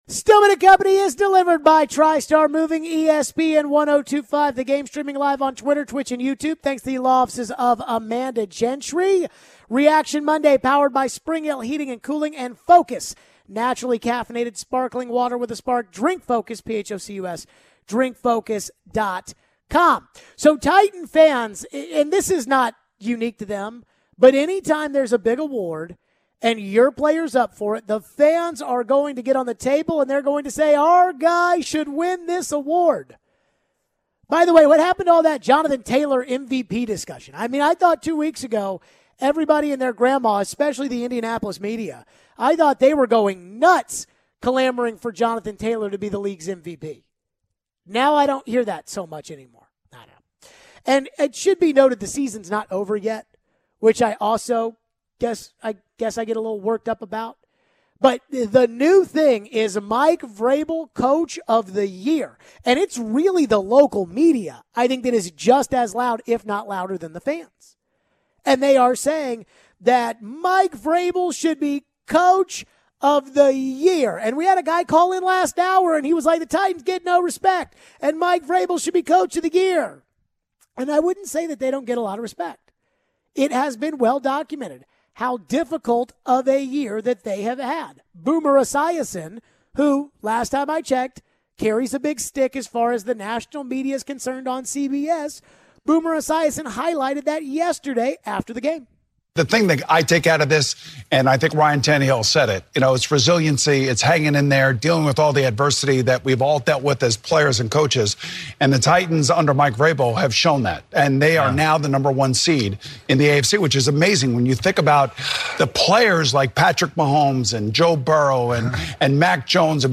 How confident are we with Vrabel as coach going into the playoffs? We go back to the phones. We wrap up with what Vrabel said about Henry maybe practicing this week and how week 18 in the NFL is looking.